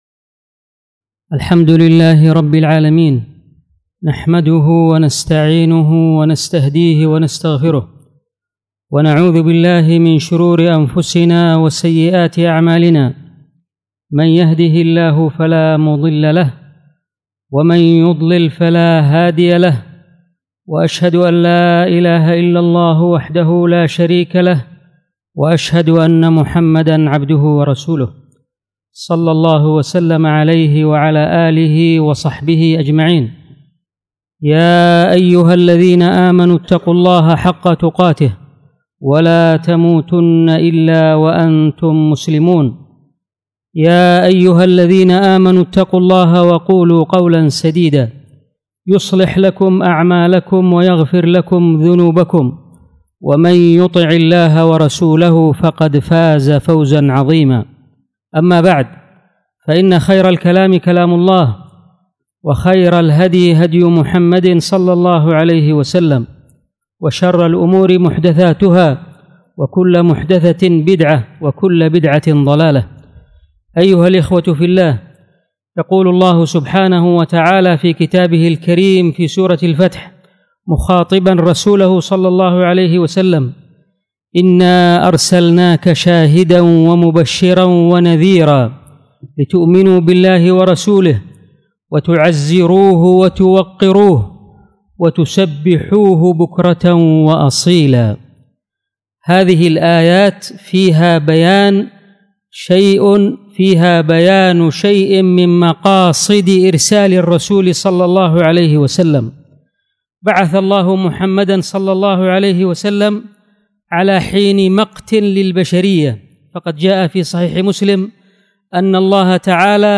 الخطبه
تعزير الرسول وتوقيره خطب الجمعة